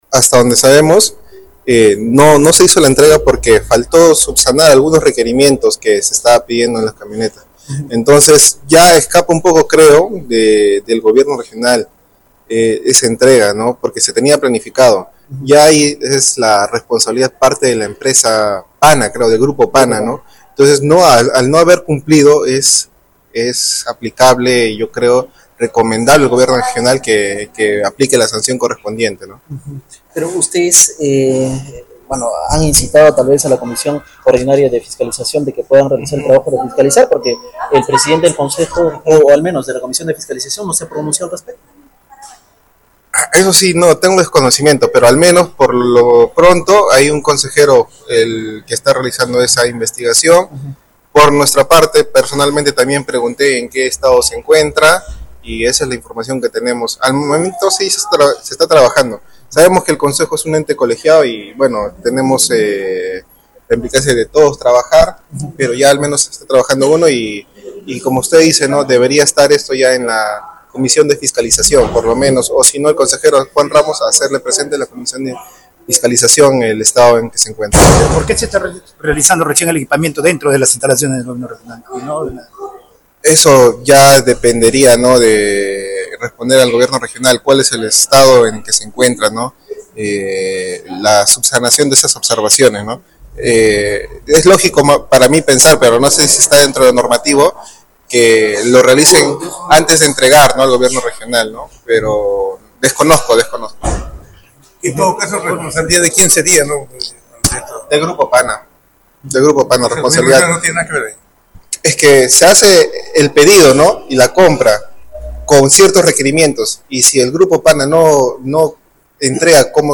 «No se hizo la entrega porque faltó subsanar algunos requerimientos que se estaba pidiendo de las camionetas, ya escapa un poco creo del Gobierno Regional esa entrega porque se tenía planificado. Ya la responsabilidad parte del Grupo Pana al no haber cumplido, es recomendable al Gobierno Regional aplique la sanción correspondiente», dijo este jueves 2 de enero a la salida de ceremonia de inicio de año judicial 2025.
fred-calizaya-consejero-regional-de-tacna.mp3